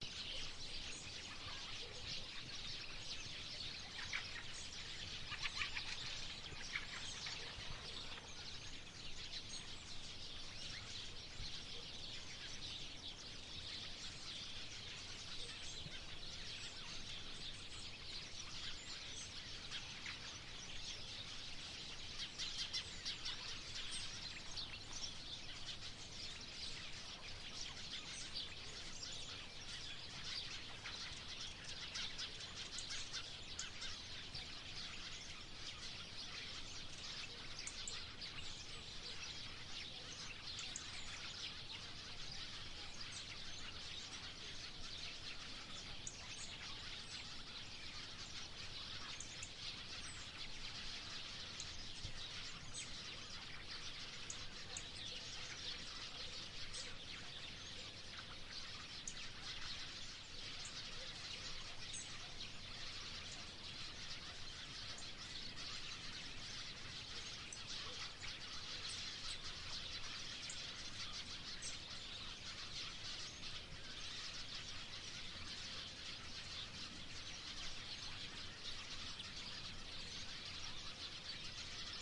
声音效果 " 许多麻雀遥远的城市秋天的前线
描述：使用Zoom H2n录制。在布达佩斯街的春天
Tag: 麻雀 现场记录 H 2 N 啁啾